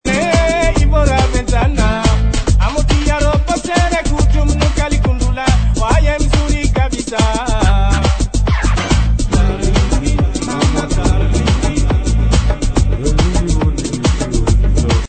world music influences Comores